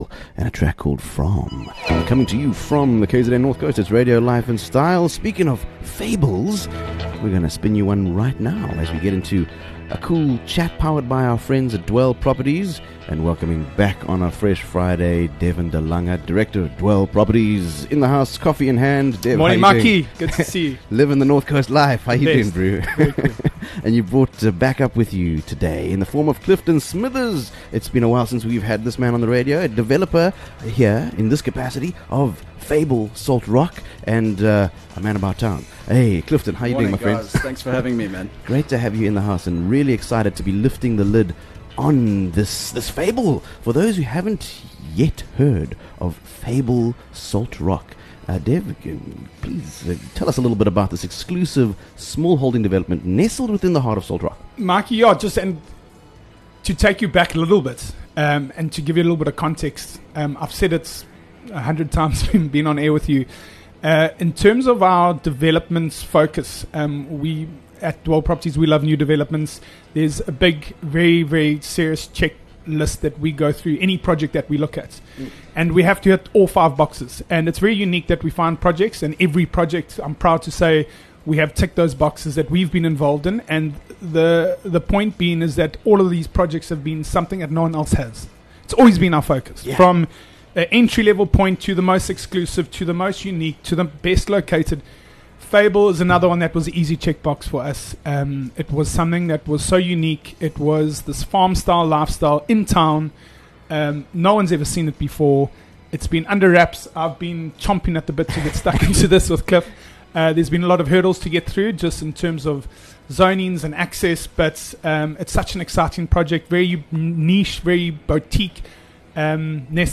This interview